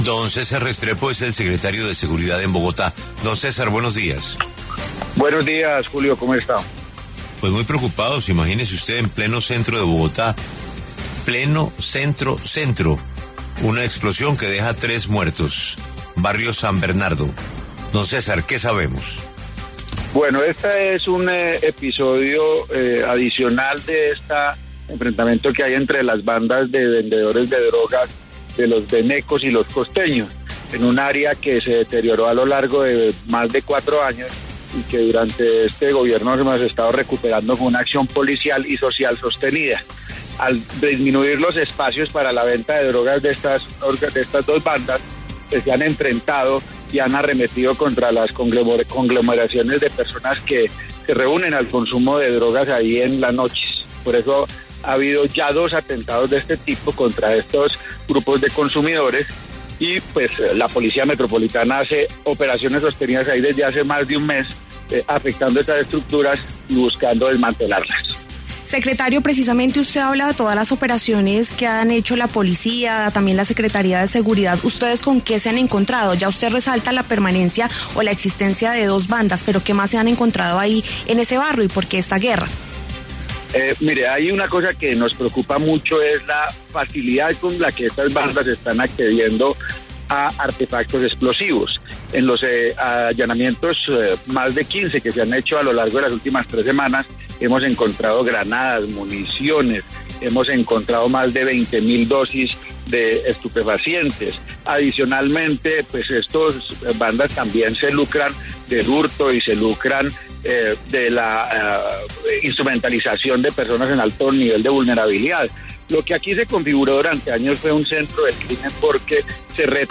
En los micrófonos de W Radio, el secretario de Seguridad de Bogotá, César Restrepo, habló sobre los hechos de orden público que se presentaron en el barrio San Bernardo, en pleno centro de Bogotá, y que dejaron a tres personas muertas y nueve heridas.